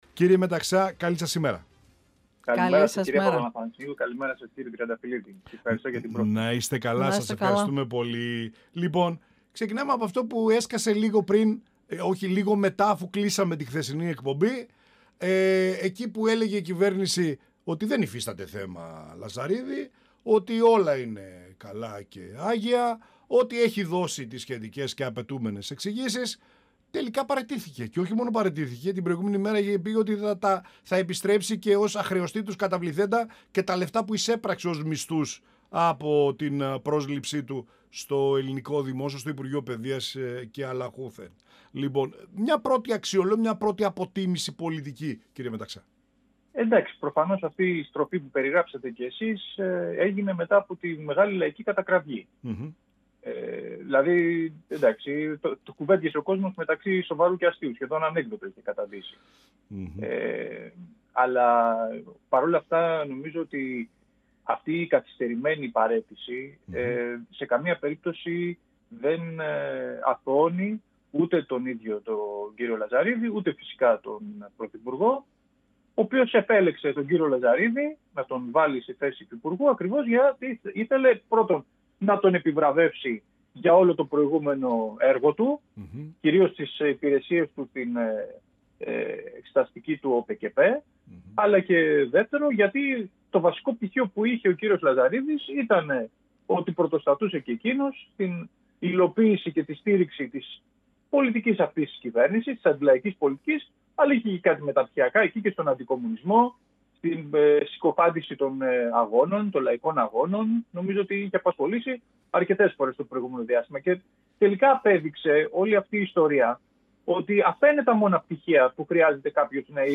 Στο θέμα της άρσεως ασυλίας των βουλευτών της ΝΔ για το σκάνδαλο του ΟΠΕΚΕΠΕ αλλά και το θέμα Λαζαρίδη και τις παρενέργειές του αναφέρθηκε ο Βουλευτής Μαγνησίας του ΚΚΕ Βασίλης Μεταξάς, μιλώντας στην εκπομπή «Πανόραμα Επικαιρότητας» του 102FM της ΕΡΤ3.
Συνεντεύξεις